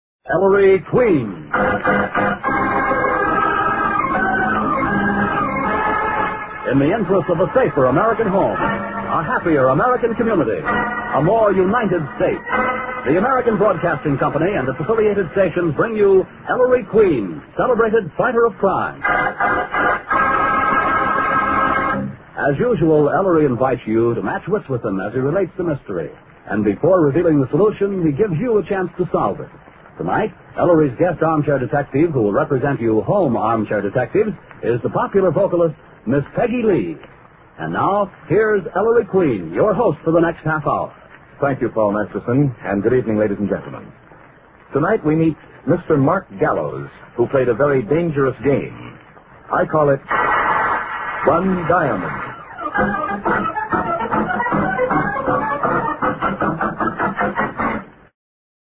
Introclip to the radioshow Episode preserved at Marr Sound Archives